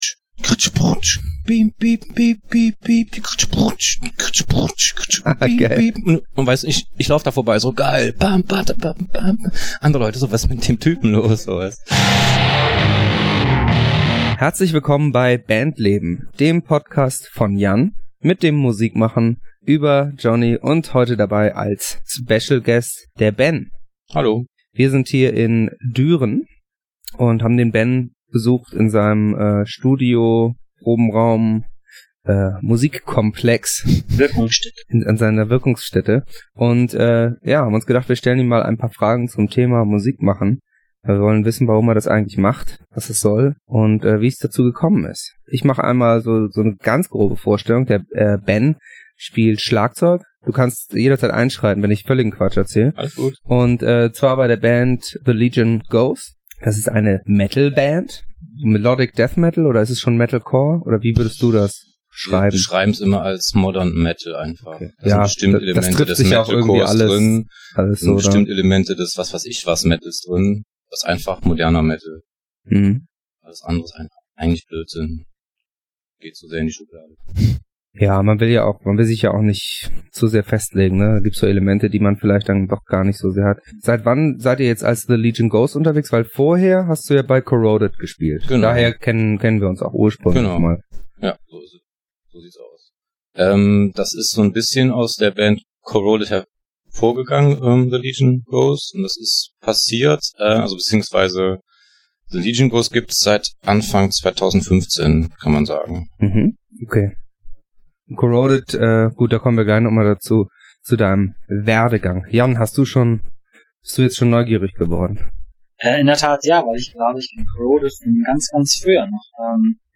*Die etwas schlechtere Audioqualität im Verhältnis zu sonst bitten wir zu entschuldigen, unser mobiles Setup muss noch etwas optimiert werden, wir arbeiten dran.